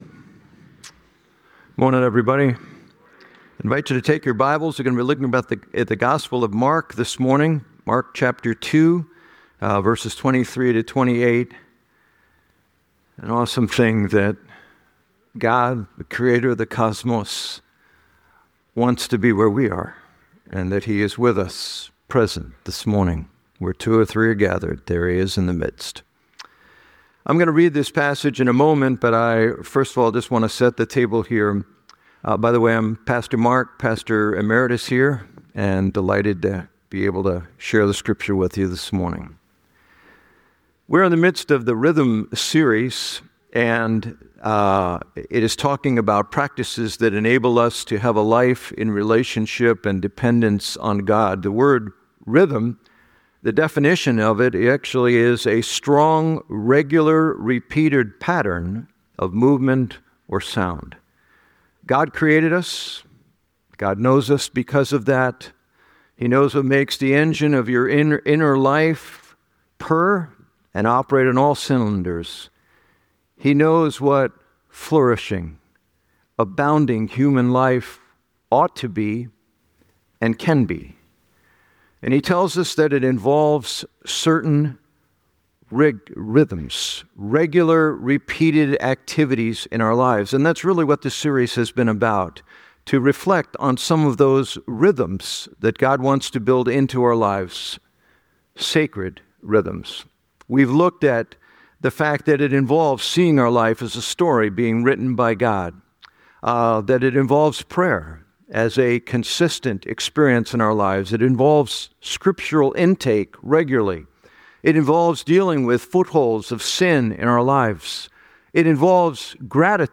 The sermon underscores the need for intentionality in living out the Sabbath, especially in a culture that often prioritizes constant activity and productivity.